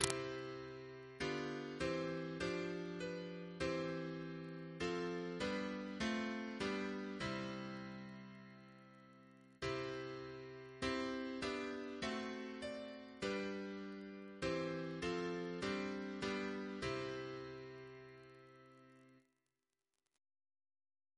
Double chant in C Composer: Sir H. Walford Davies (1869-1941), Organist of the Temple Church and St. George's, Windsor Reference psalters: ACP: 182; RSCM: 149